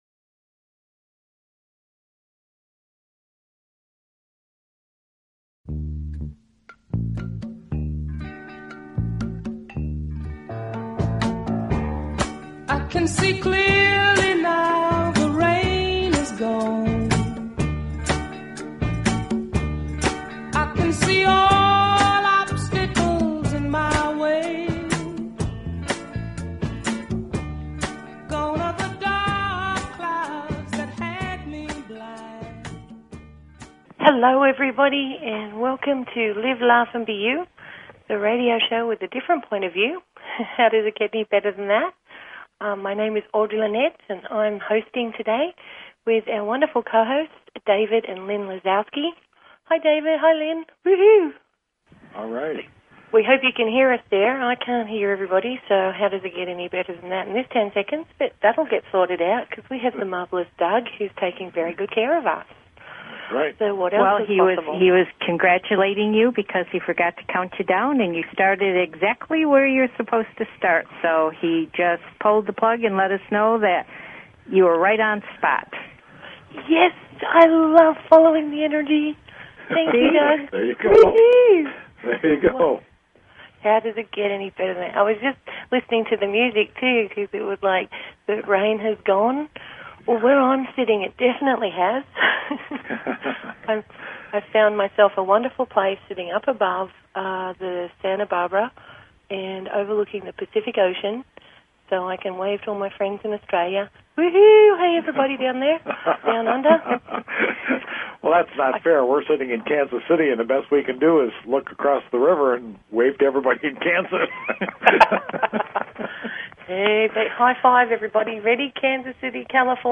Talk Show Episode, Audio Podcast, Live_Laugh_and_BE_You and Courtesy of BBS Radio on , show guests , about , categorized as